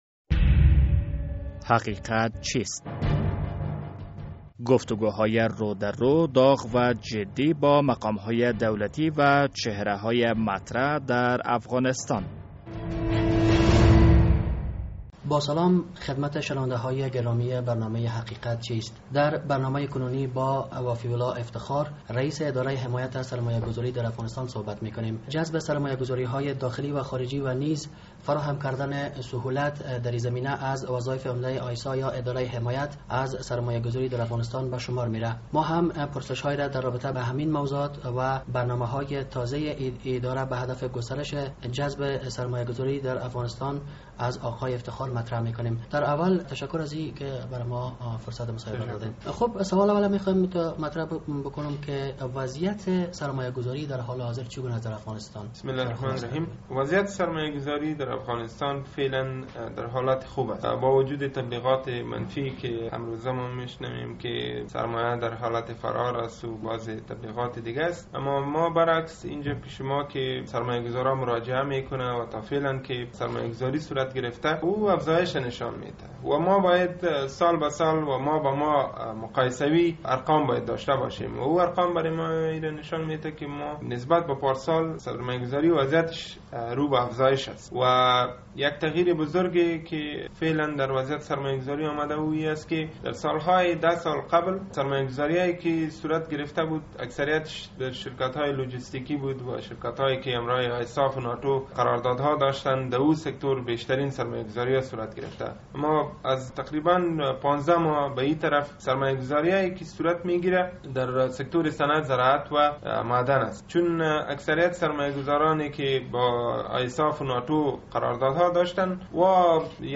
در برنامهء امروز حقیقت چیست با وفی الله افتخار رییس آیسا یا ادارهء حمایت از سرمایه گذاری در افغانستان گفتگو کرده ایم.